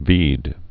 (s vēd)